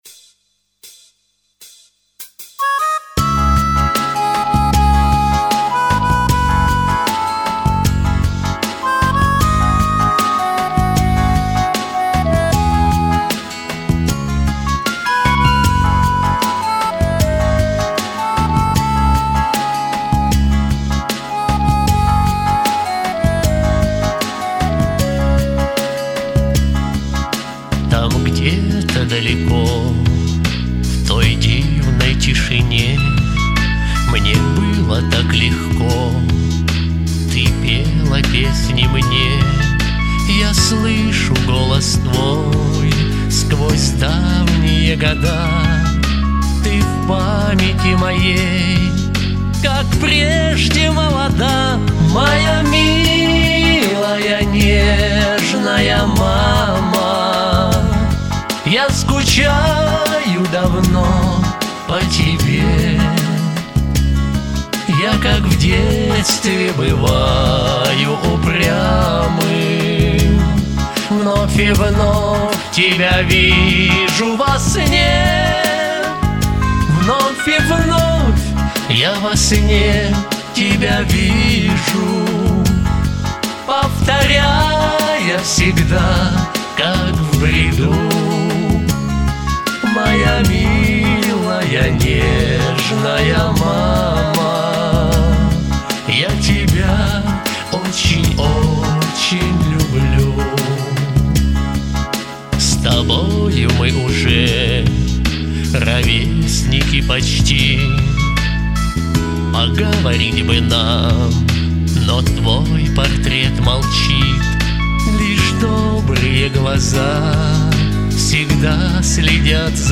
вірш щирий та зворушливий і пісня душевна...